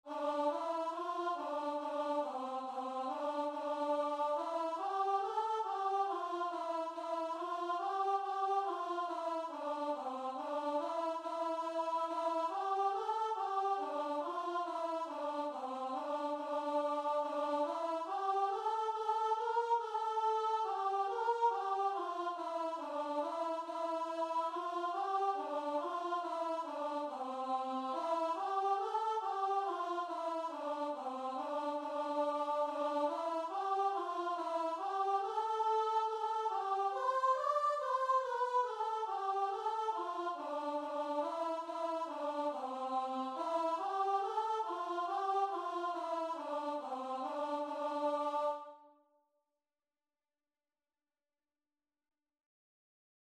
Free Sheet music for Choir
Traditional Music of unknown author.
A minor (Sounding Pitch) (View more A minor Music for Choir )
4/4 (View more 4/4 Music)
Christian (View more Christian Choir Music)